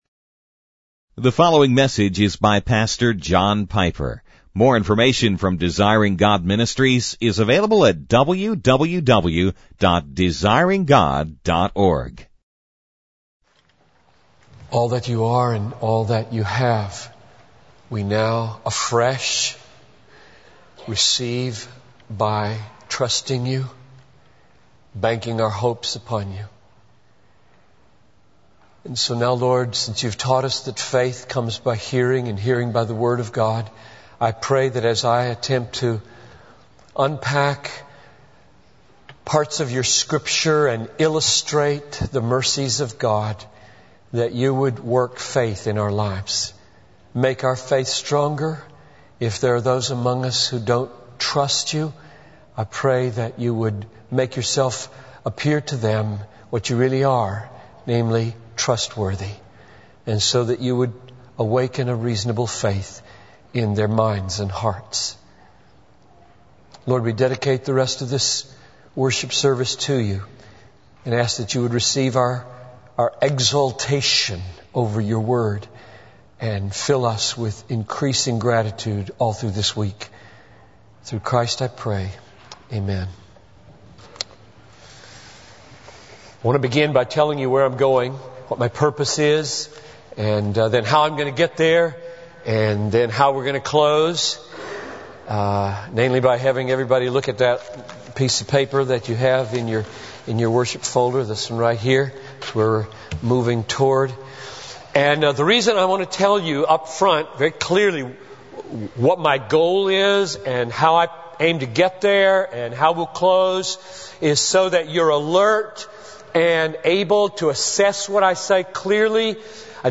In this sermon, the speaker begins by outlining his plan for the message. He will read a passage from Lamentations Chapter 3 and provide brief commentary on it.